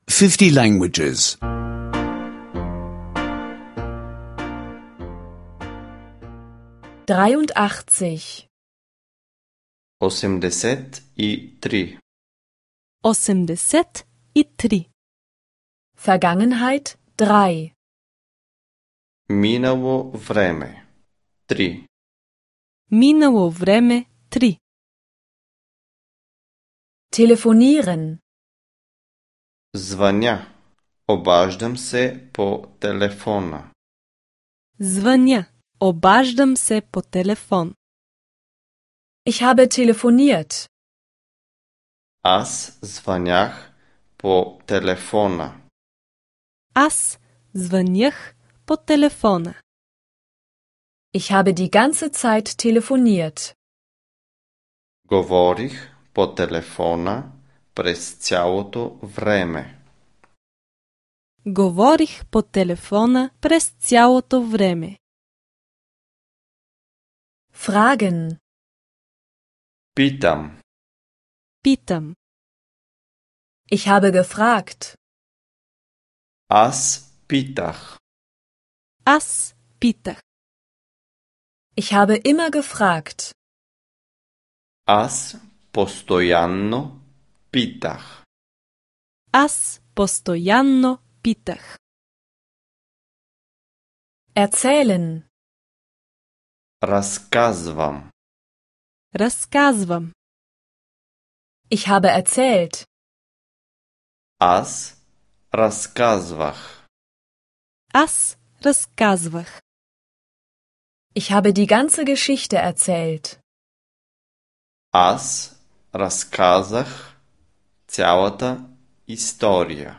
Bulgarisch Audio-Lektionen, die Sie kostenlos online anhören können.